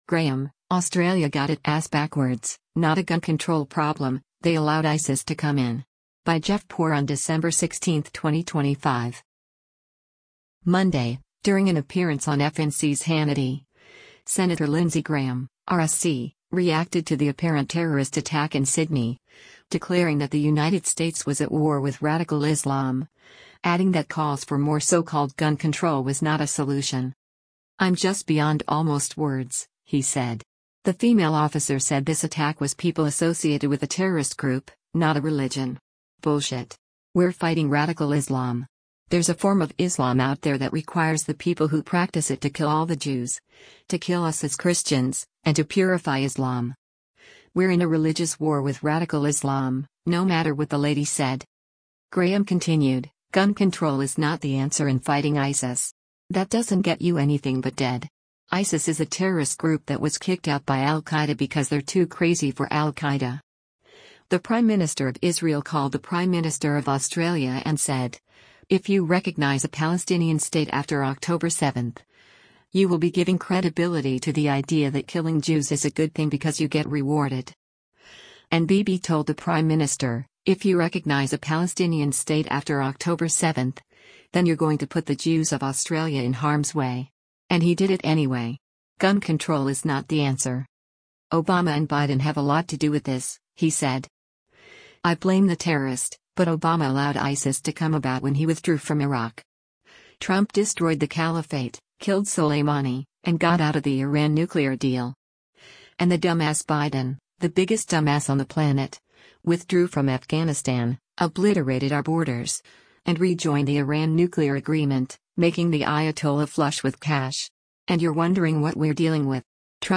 Monday, during an appearance on FNC’s “Hannity,” Sen. Lindsey Graham (R-SC) reacted to the apparent terrorist attack in Sydney, declaring that the  United States was “at war” with radical Islam, adding that calls for more so-called gun control was not a solution.